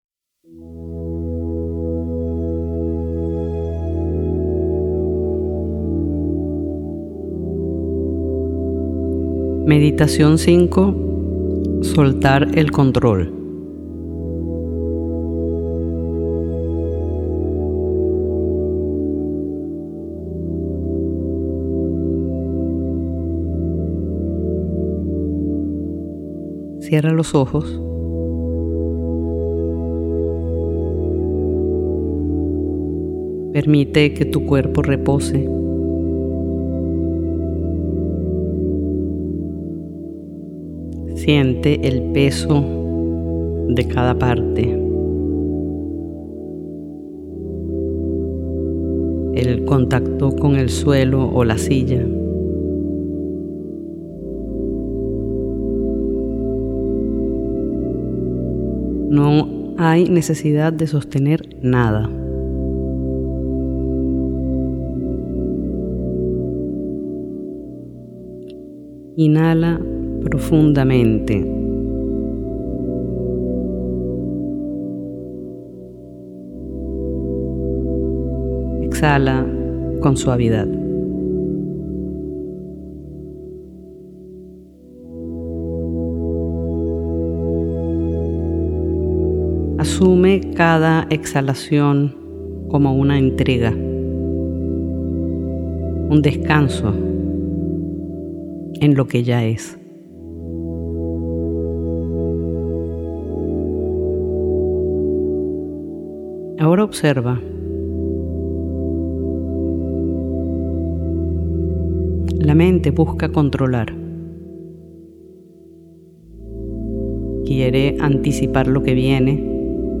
21 Meditaciones para sumergirse en la presencia es una colección única, donde música y voz se entrelazan para abrir un espacio de contemplación y quietud.
Cada meditación dura aproximadamente 10 minutos e integra: La palabra como invitación a volver al centro. Música inmersiva que conduce suavemente hacia el silencio.